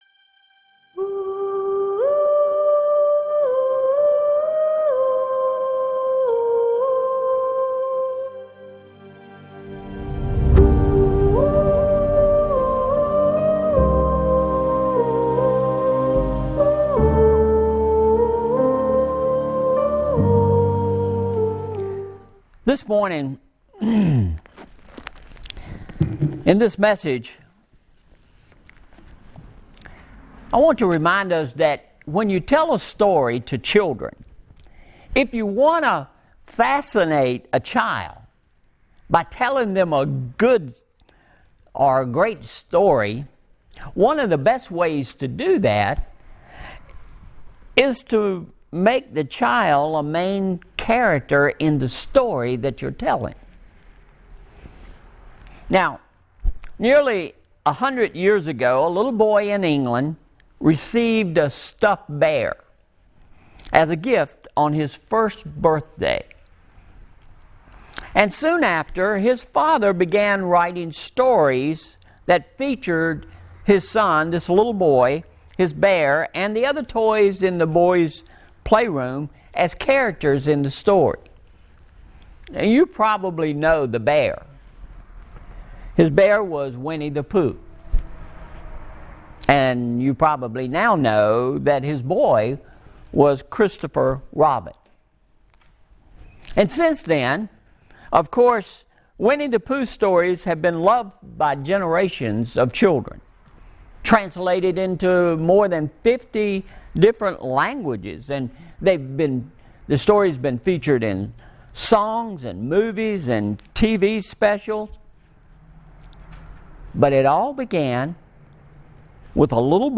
Sermon Synopsis